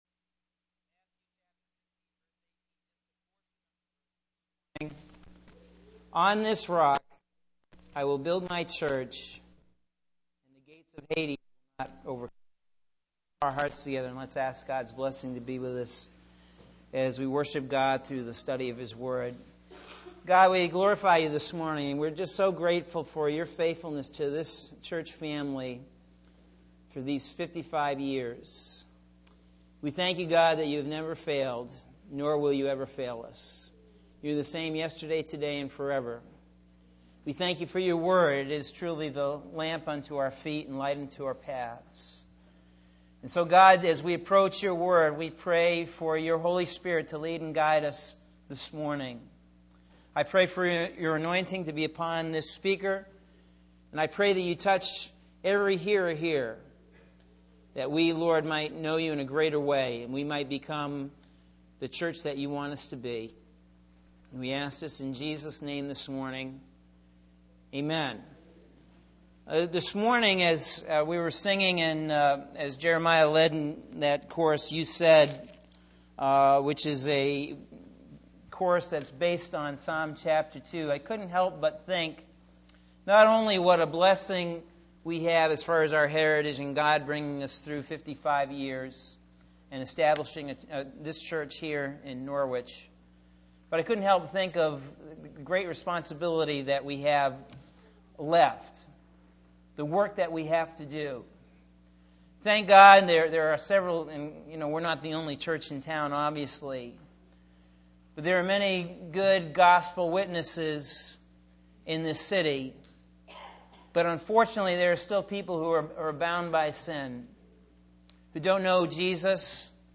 Sunday March 7th – AM Sermon – Norwich Assembly of God